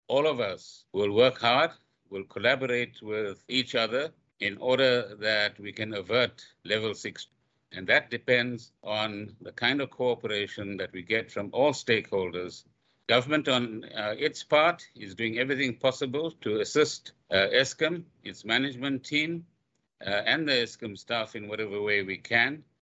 Gordhan yesterday, during a press briefing, announced that a wage agreement was reached yesterday between unions and Eskom.